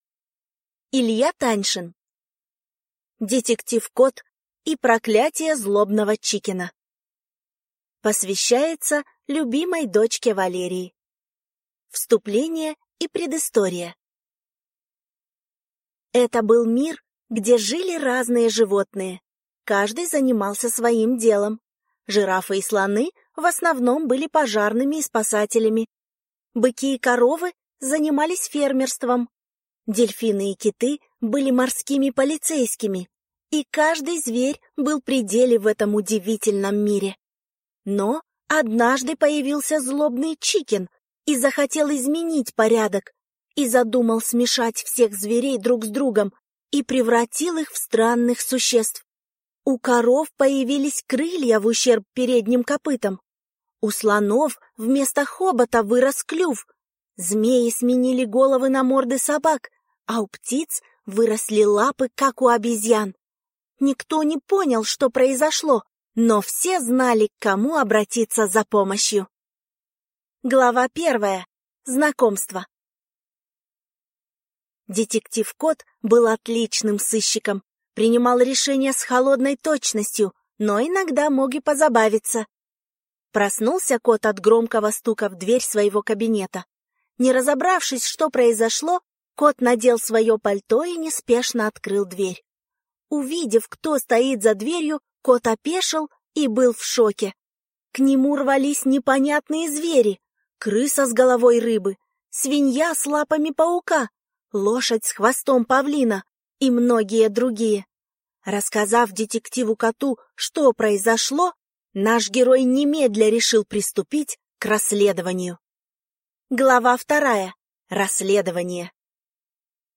Аудиокнига Детектив Кот и Проклятие Злобного Чикена | Библиотека аудиокниг